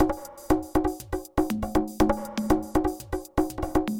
打击乐组合Uptempo 002 (120 BPM)
描述：打击乐动作或戏剧电影。 120 BPM
声道立体声